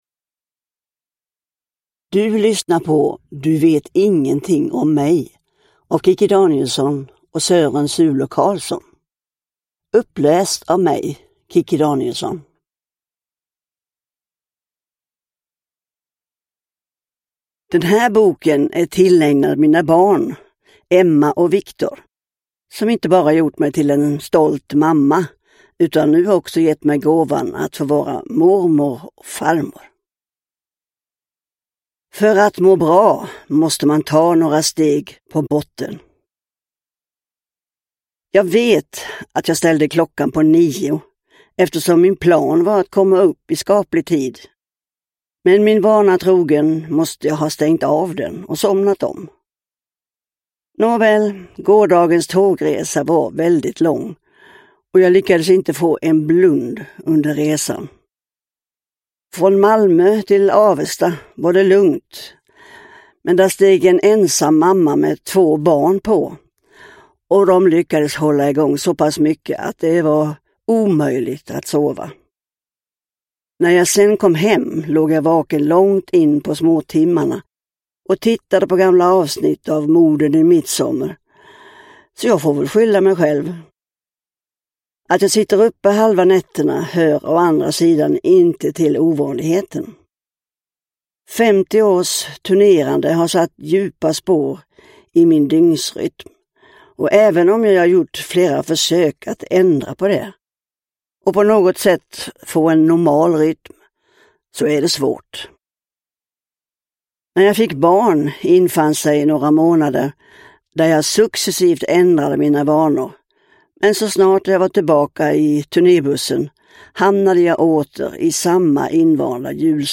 Du vet ingenting om mig – Ljudbok
Uppläsare: Kikki Danielsson